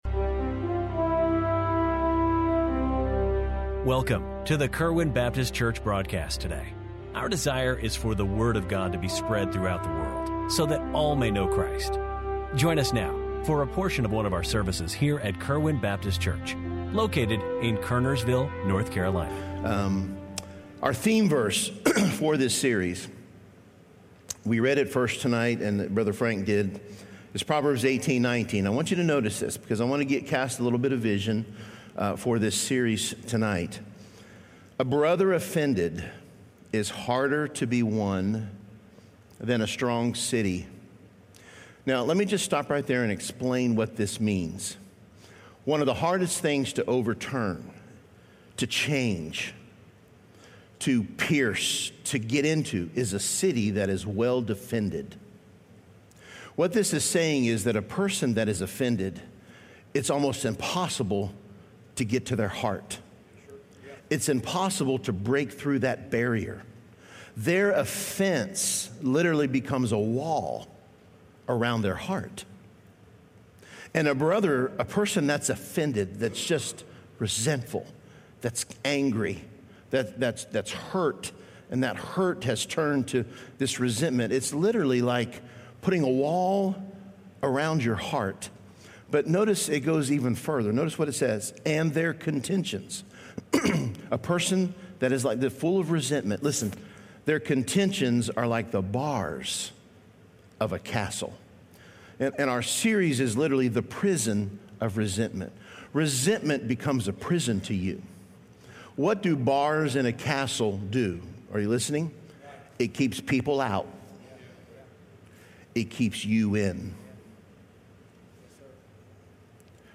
1-6-26 Kerwin Baptist Church Daily Sermon Broadcast